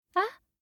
알림음 8_WomanYes2.mp3